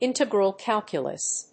íntegral cálculus
音節ìntegral cálculus